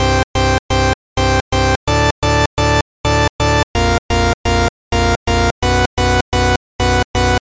风琴的旋律
描述：一个简单的管风琴旋律，使用西伦特1号制作。 (:
标签： 128 bpm House Loops Organ Loops 1.26 MB wav Key : Unknown
声道立体声